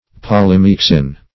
polymyxin.mp3